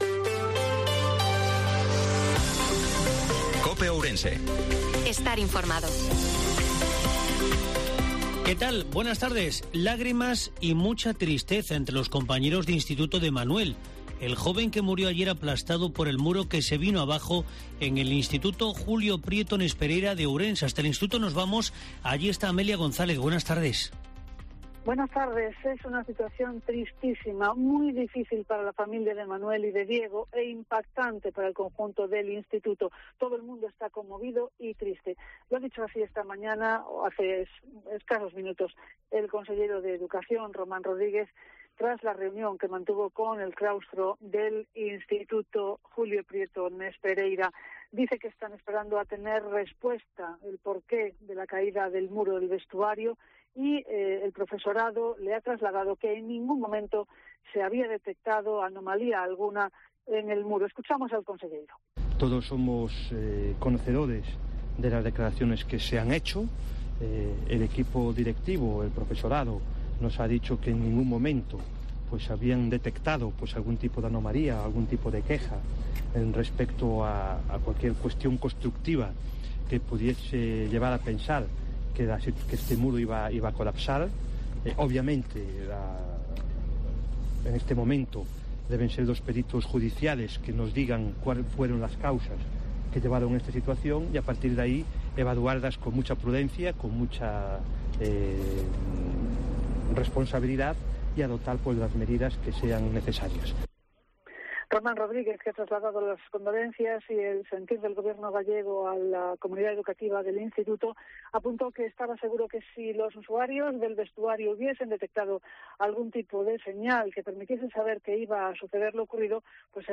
INFORMATIVO MEDIODIA COPE OURENSE-27/10/2022